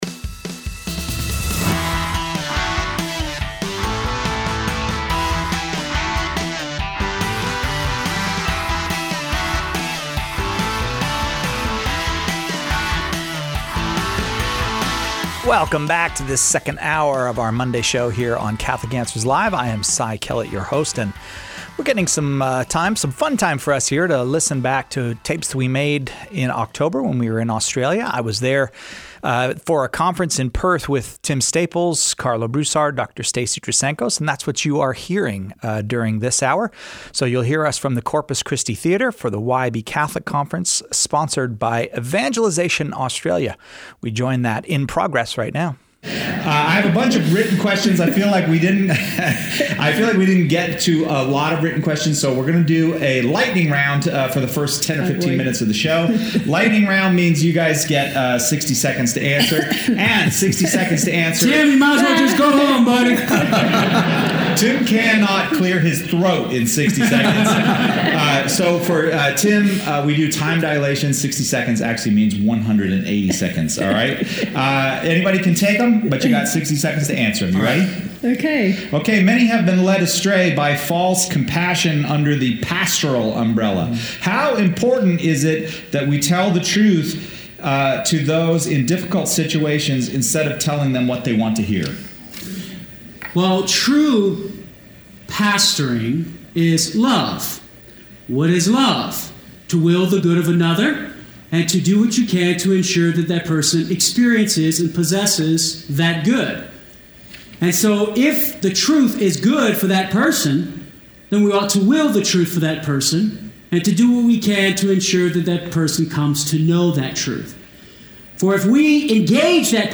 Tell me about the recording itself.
Hear an hour of open forum in Australia!